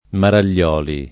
[ maral’l’ 0 li ]